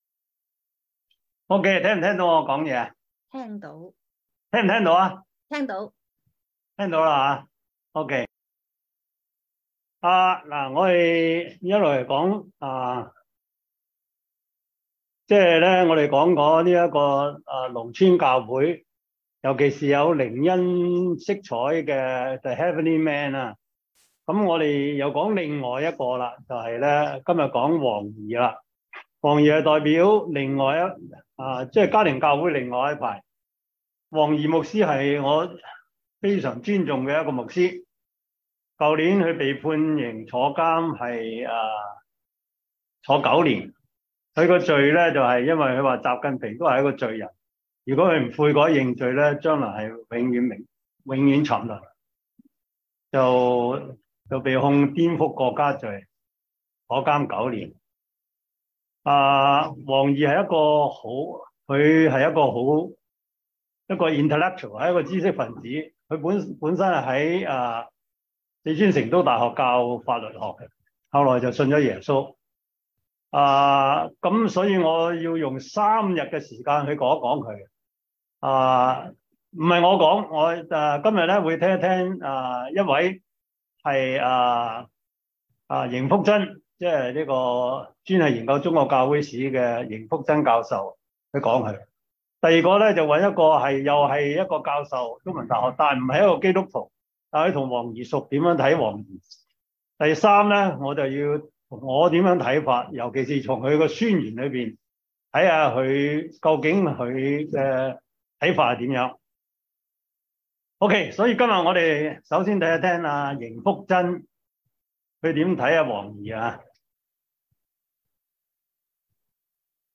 教會歷史 Service Type: 中文主日學 中國教會史 – 第四季 第六十課